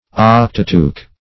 Search Result for " octateuch" : The Collaborative International Dictionary of English v.0.48: Octateuch \Oc"ta*teuch\, n. [L. octateuchus, Gr. ?.] A collection of eight books; especially, the first eight books of the Old Testament.